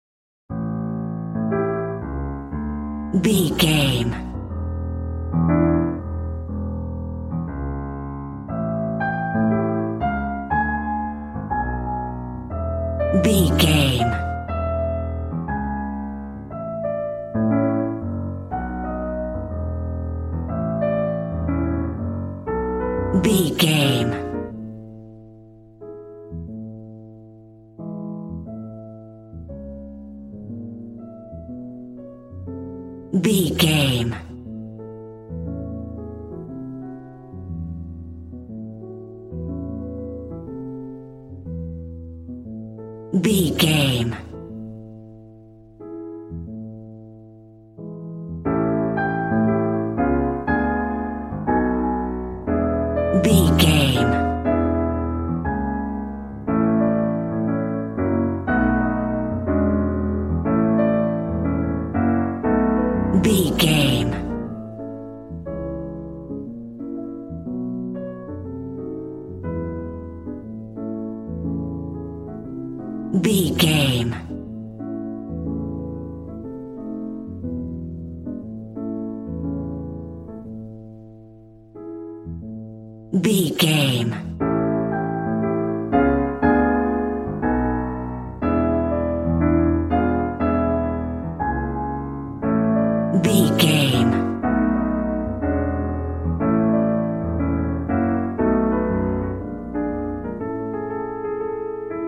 Ionian/Major
cool
sexy
piano